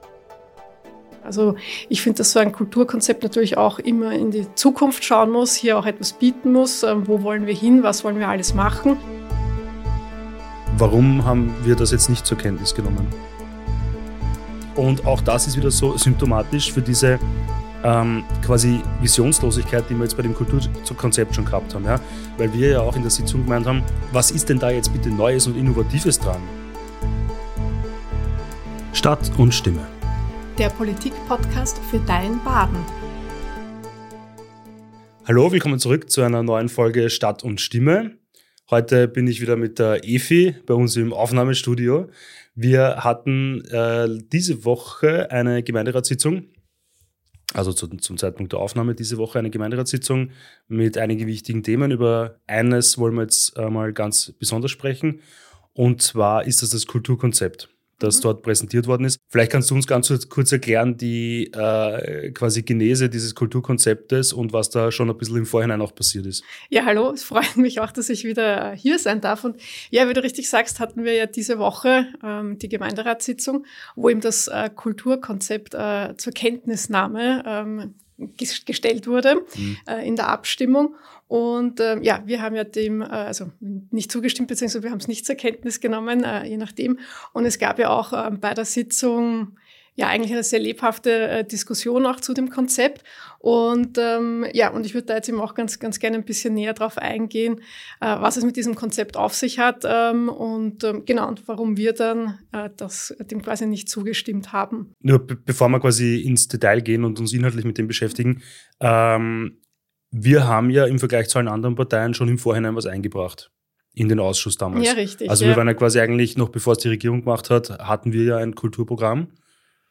Stadtrat Stefan Eitler und Gemeinderätin Mag. Eva Freistetter besprechen die Ergebnisse der jüngsten Gemeinderatssitzung – und erklären, warum die Grünen das neu präsentierte Kulturkonzept der Jeitler-Cincelli-Regierung nicht zur Kenntnis genommen haben.